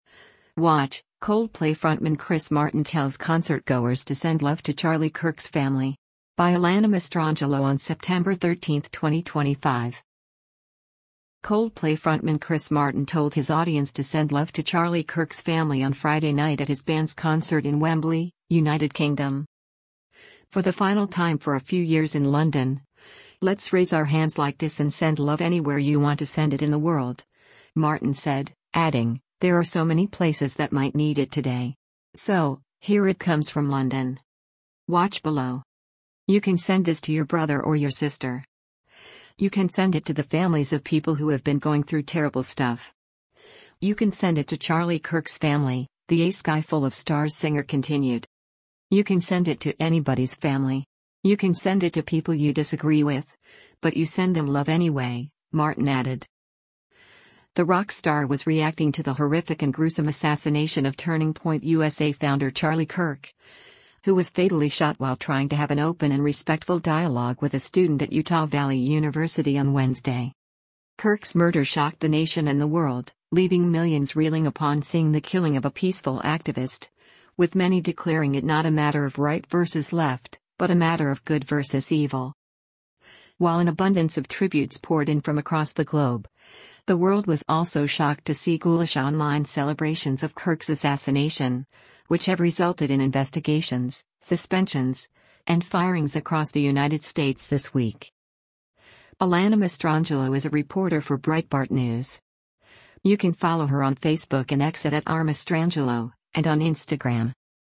Coldplay frontman Chris Martin told his audience to “send love” to Charlie Kirk’s family on Friday night at his band’s concert in Wembley, United Kingdom.